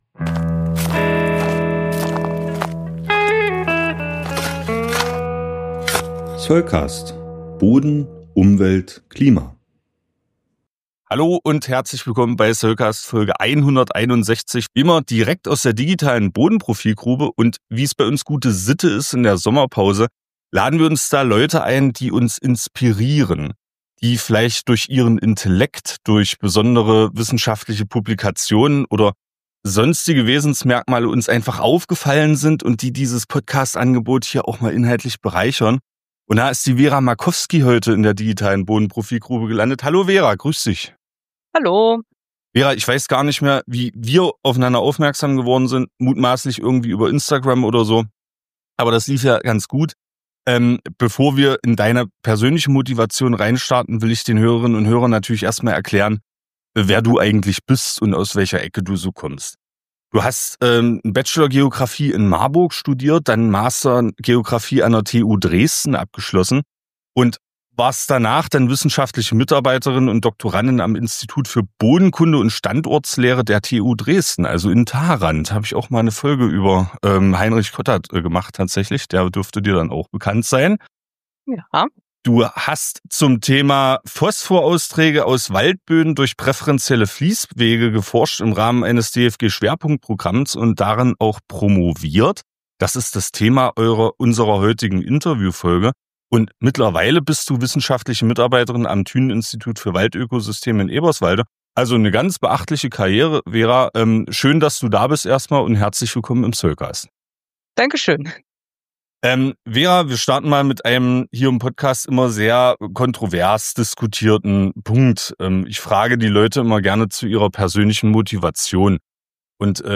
SC161 Interview: Alerta, alerta, Fósforo!